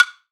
1ST-WBLOCK-L.wav